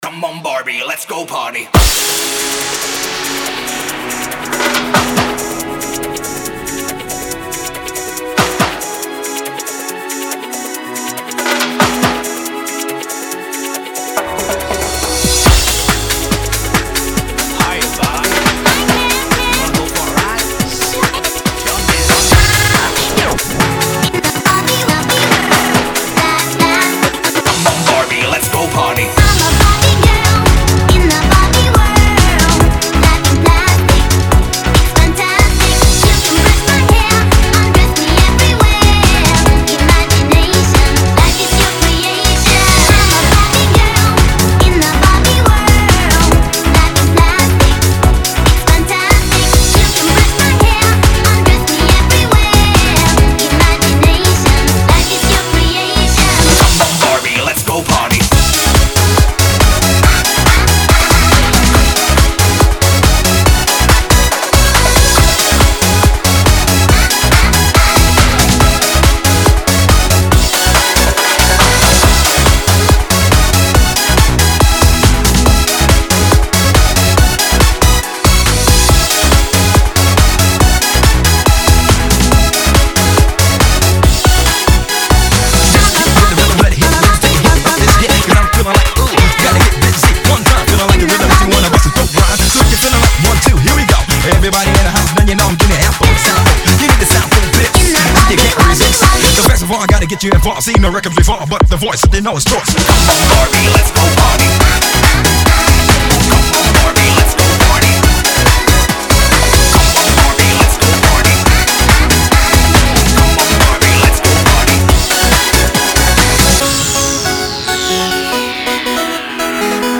a mash-up